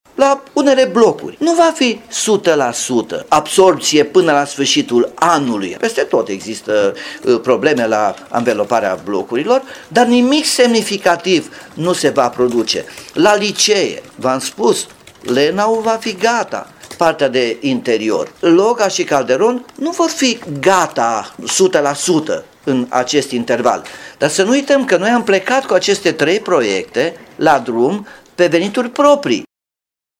Primarul Nicolae Robu spune însă că este vorba despre sume mici ce vor fi acoperite de la bugetul local.